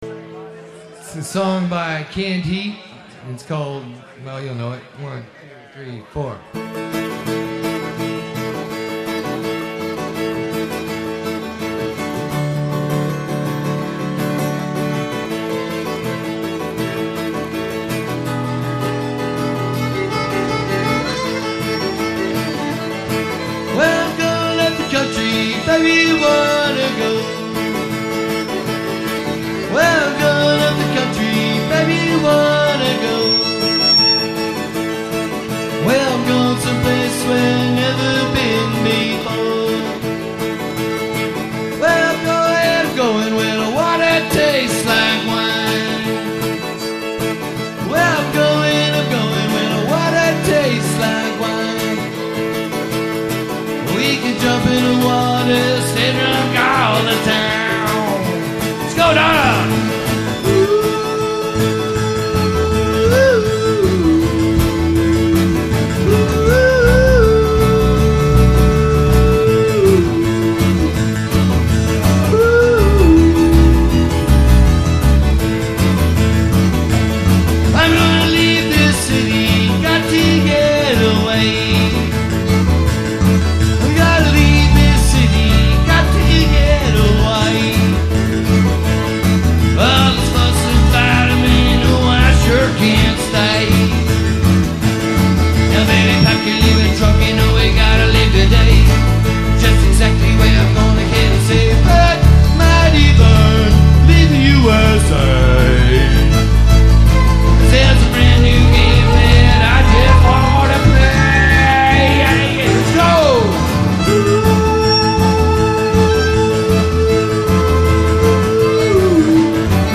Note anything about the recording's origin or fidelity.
at the second "Coyotes and Friends" event